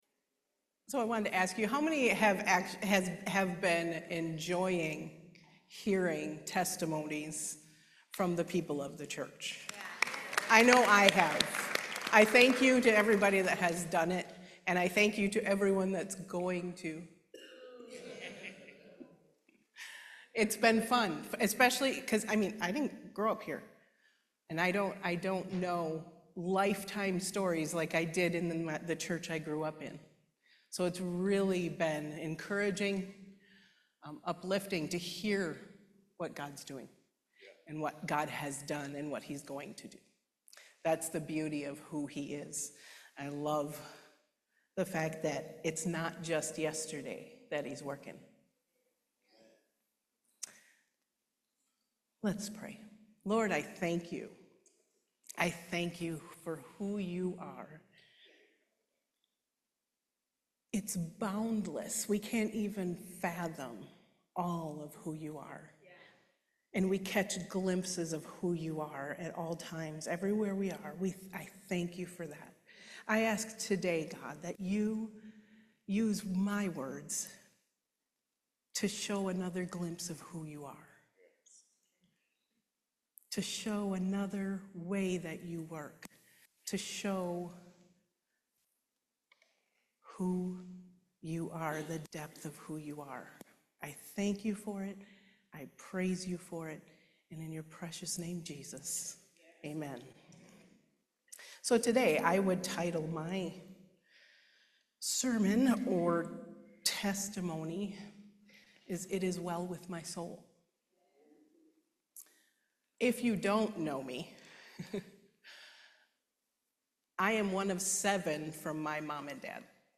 Series: Testimony
Service Type: Main Service